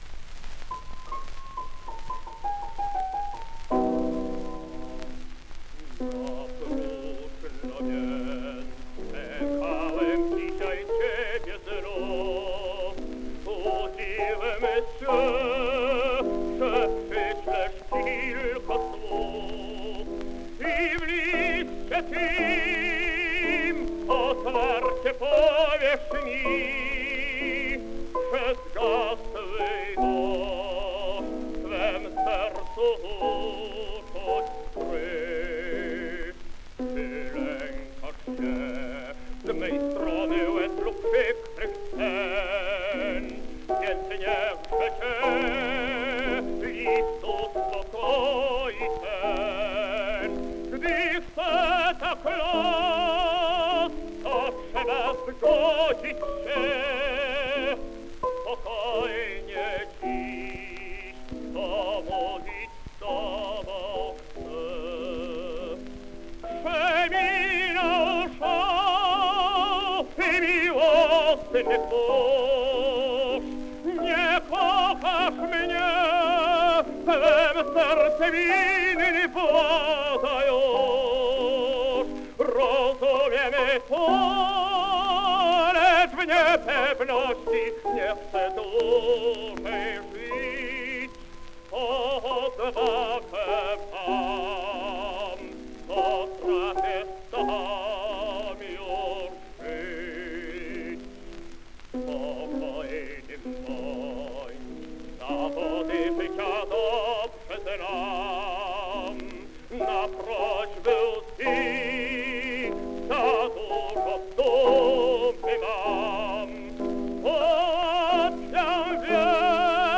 Польская версия австро-венгерского шлягера